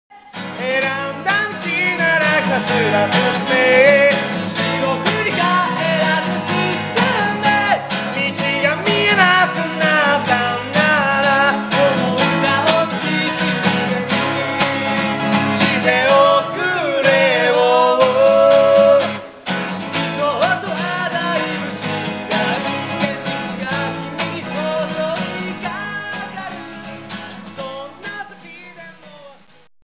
Gt,ハープ
Gt,タンバリン、ピアニカ
僕達のライブ中の楽曲と映像が以下で見られます。